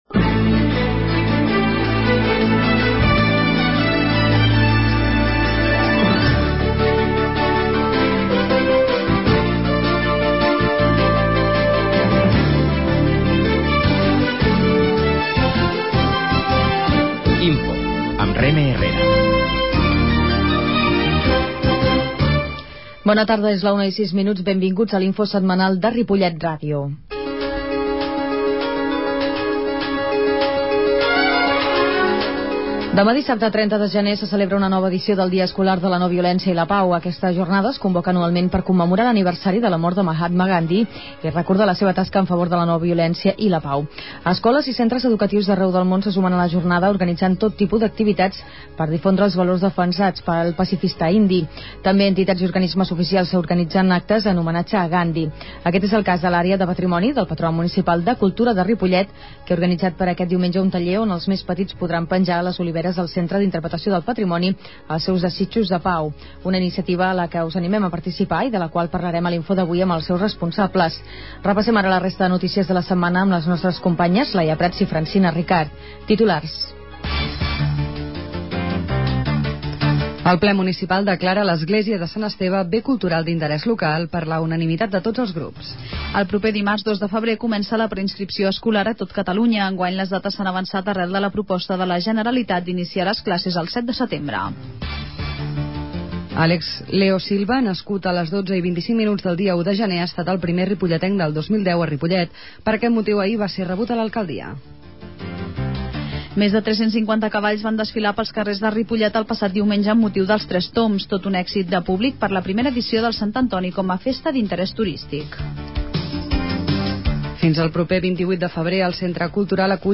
Redifusi� i desc�rrega per Internet Escolteu en directe per la r�dio o la xarxa el resum de not�cies de Ripollet R�dio (91.3 FM), que s'emet en directe a les 13 hores.
La qualitat de so ha estat redu�da per tal d'agilitzar la seva desc�rrega.